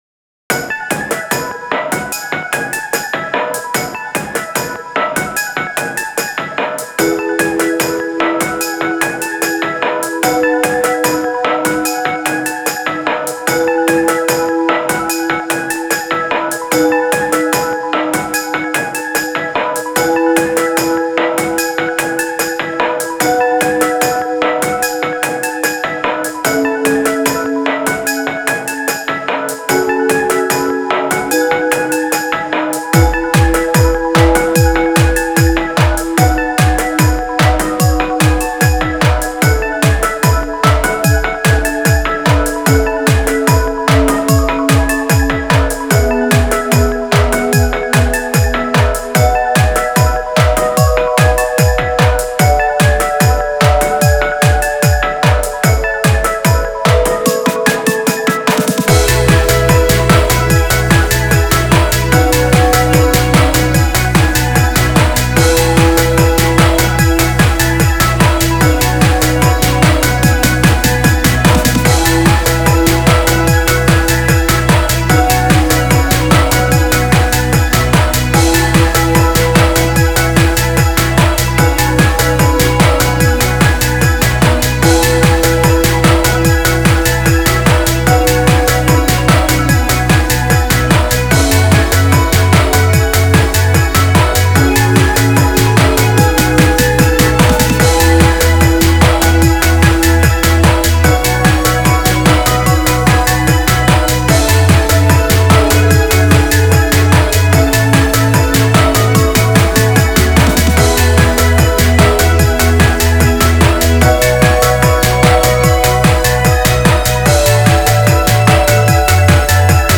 テクノやブレイクビーツを中心としたオリジナル楽曲を公開しています。